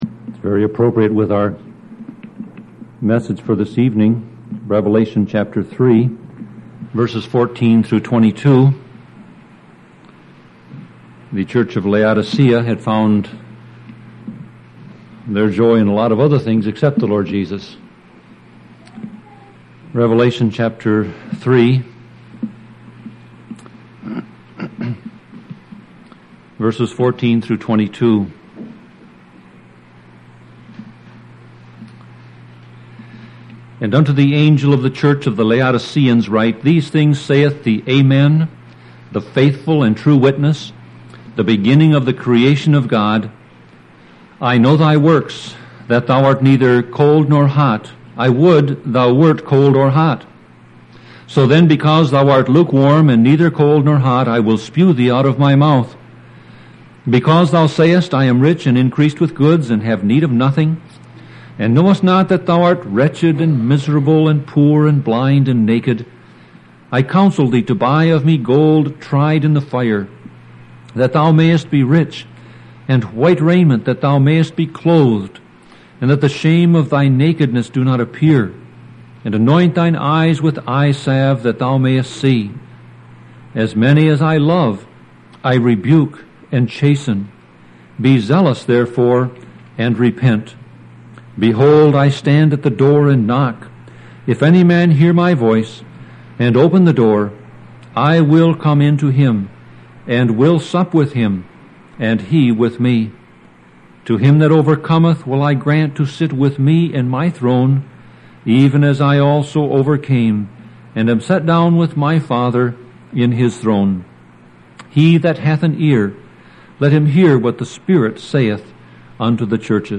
Sermon Audio Passage: Revelation 3:14-22 Service Type